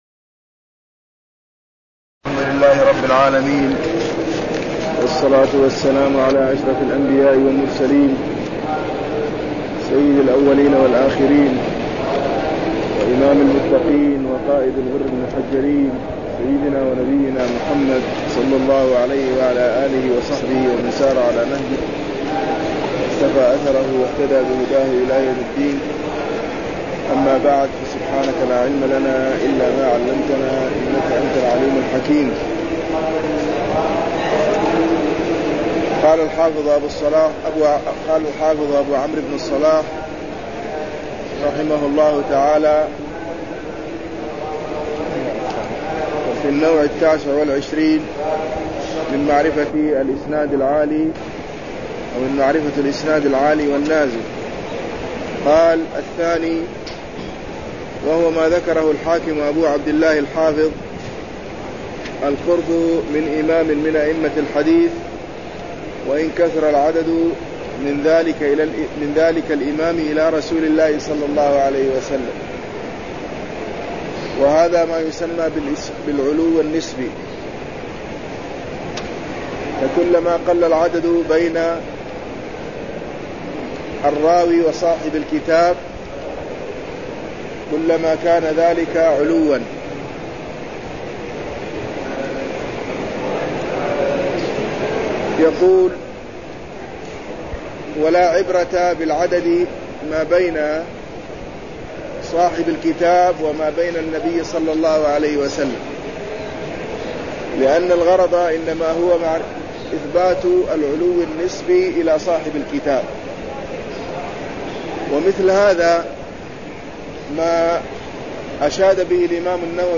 درس في كتاب النكاح (012)
المكان: المسجد النبوي